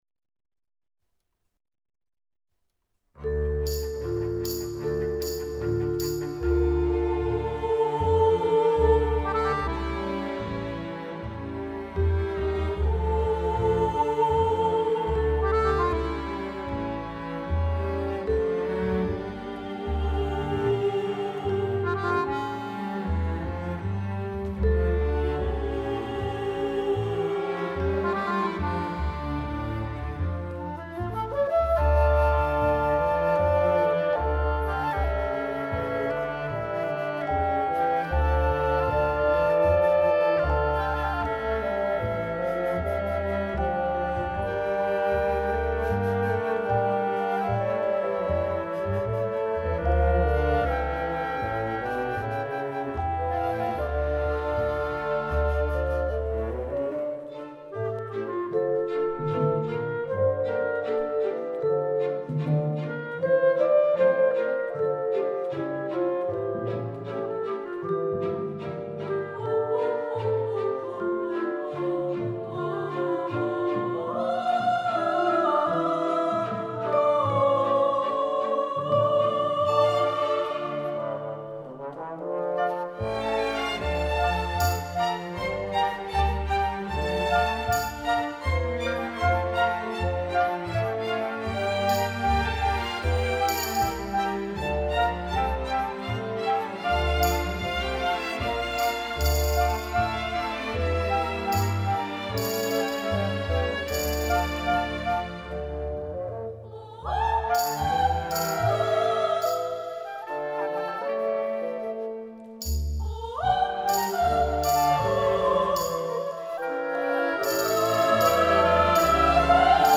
Επτά μουσικά θέματα ενορχηστρωμένα από τον συνθέτη
όπως και ελεύθερα  μουσικά θέματα κινηματογραφικής χροιάς.